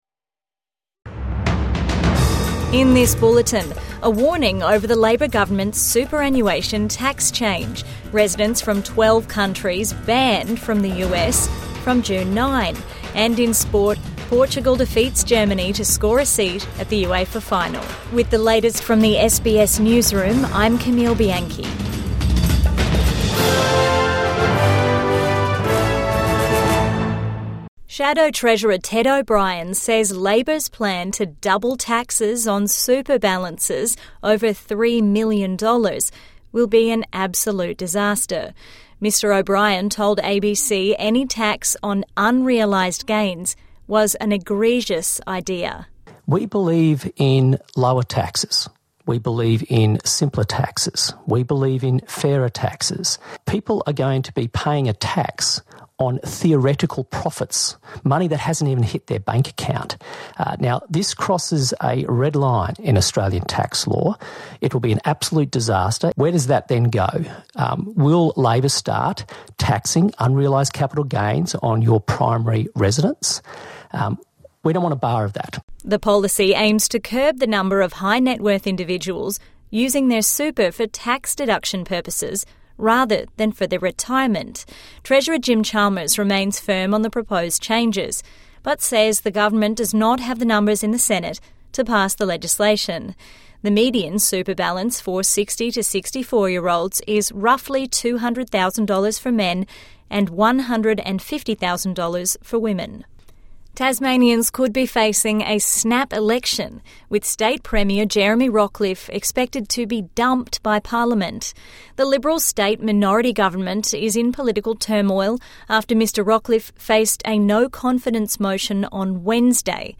Warning over impact of superannuation tax | Midday News Bulletin 6 May 2025